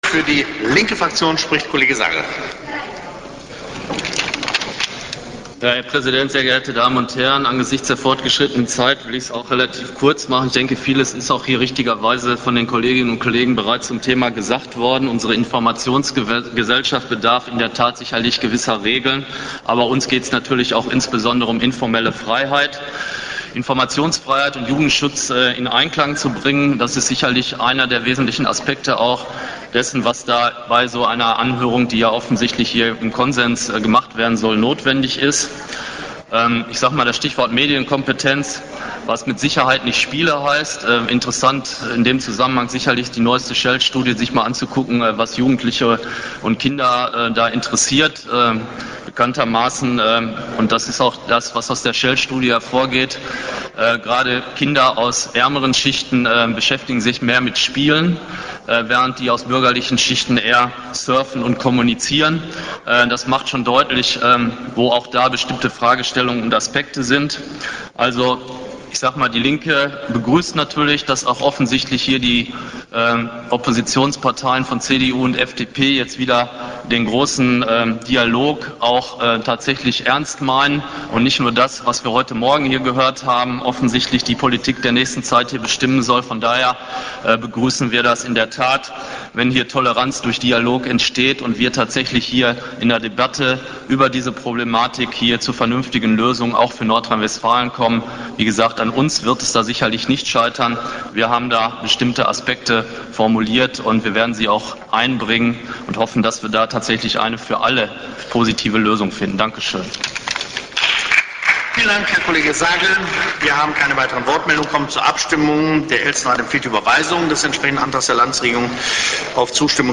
Falls jemand noch einmal reinhören möchte, ich war so frei, die Redebeiträge aus dem aufgezeichneten Real-Media-Stream zu befreien (Sorry für die lausige Qualität):
Redebeitrag Rüdiger Sagel (Die Linke)